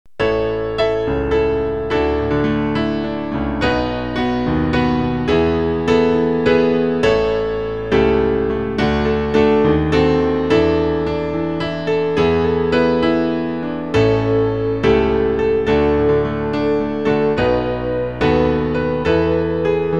Klavier-Playback zur Begleitung der Gemeinde
(ohne Gesang)